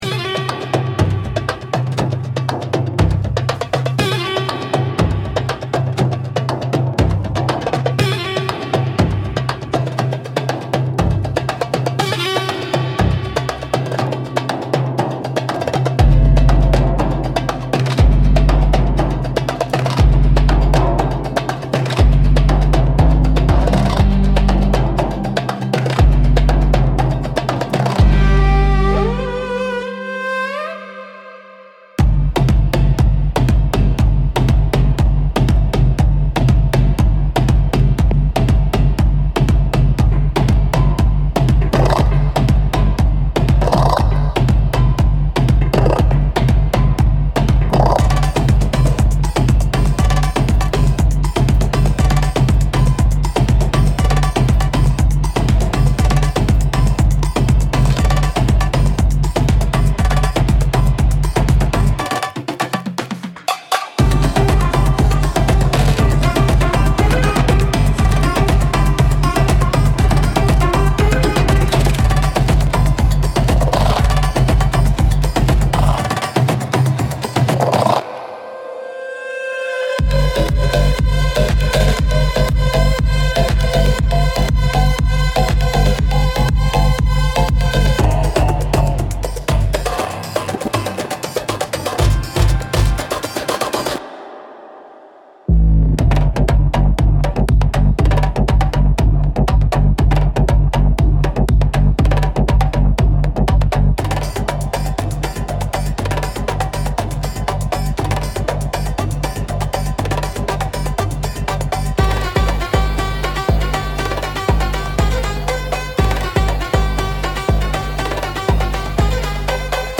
Instrumental - Temple of Fire